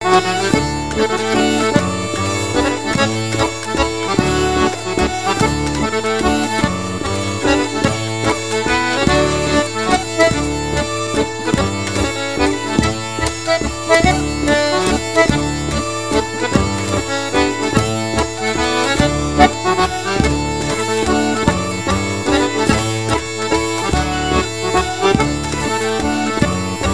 danse : mazurka